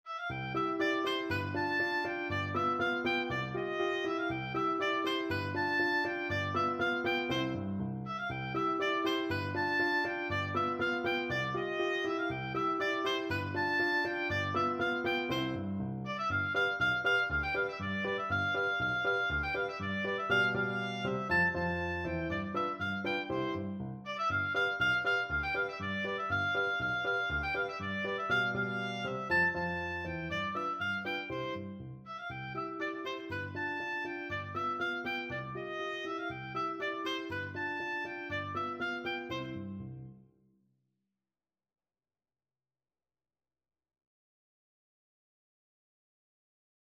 C major (Sounding Pitch) (View more C major Music for Oboe )
Allegro (View more music marked Allegro)
2/4 (View more 2/4 Music)
Oboe  (View more Easy Oboe Music)
Classical (View more Classical Oboe Music)